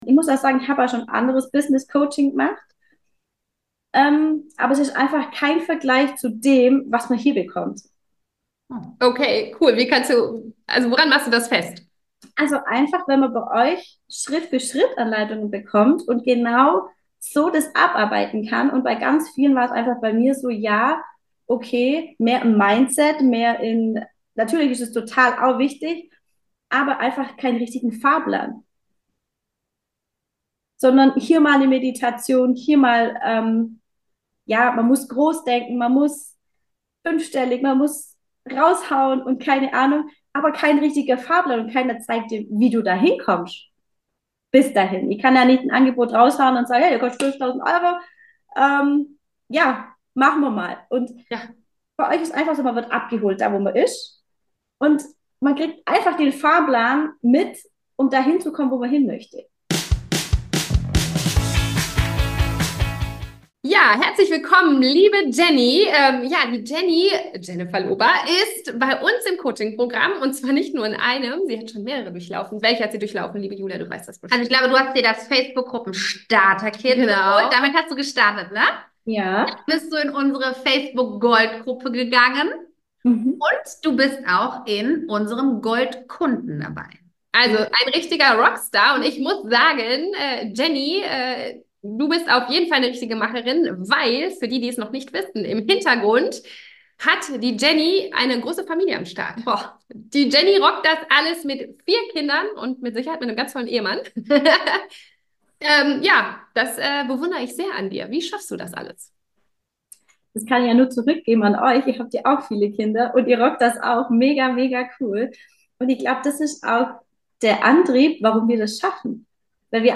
Businessstart mit funktionierender Facebook Gruppen Strategie - Erfolgsinterview